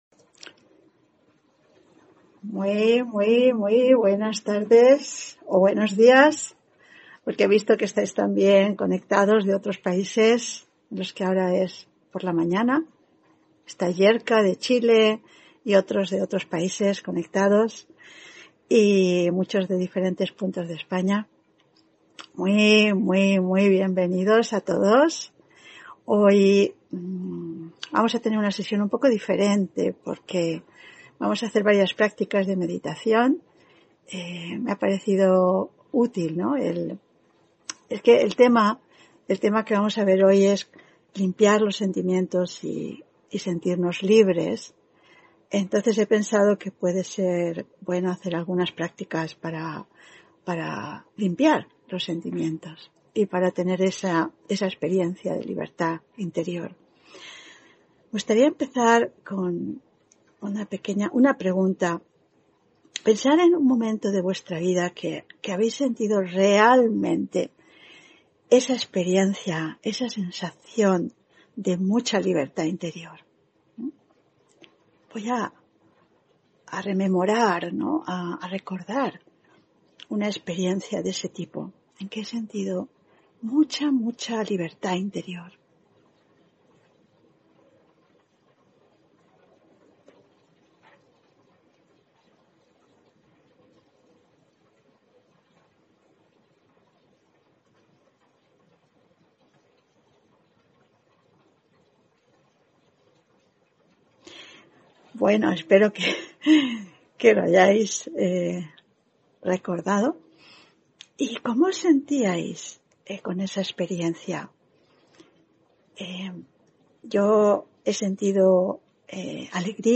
Audio conferencias